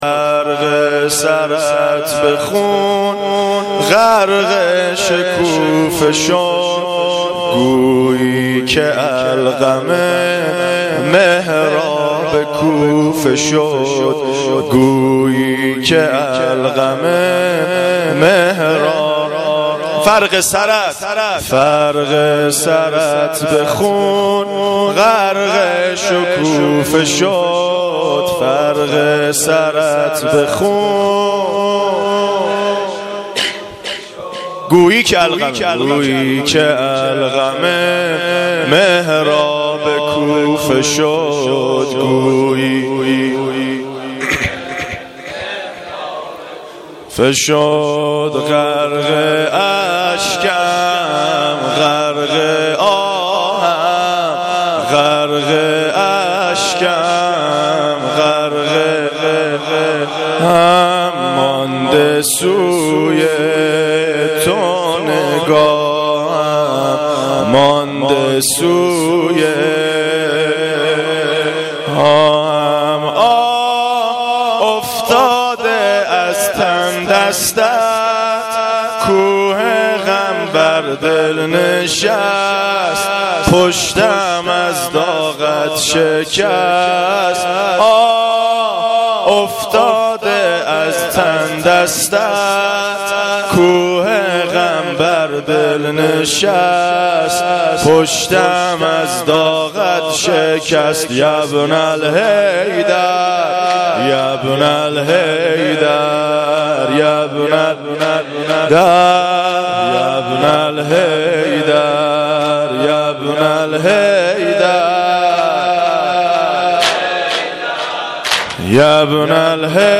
نوحه
nohe.mp3